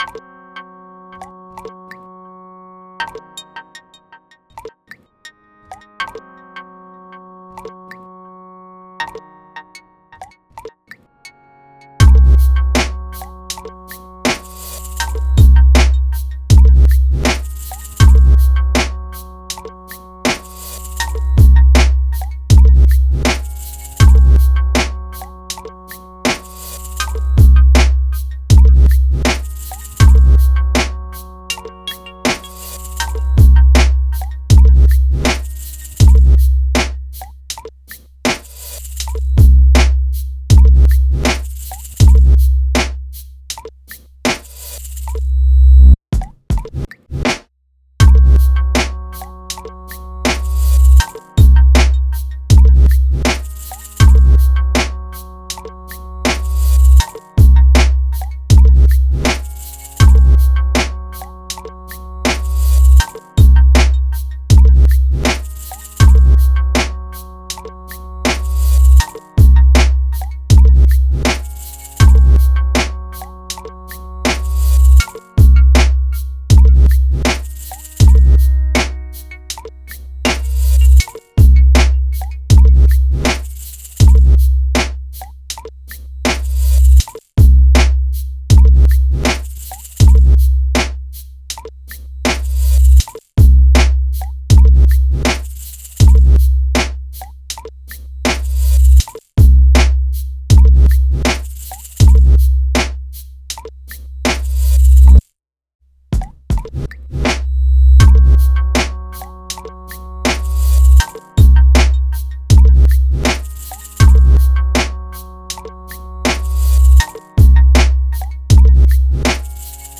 Playing around with rhythmic water drops as base percussion- I put a “bending bass” effect over it as well.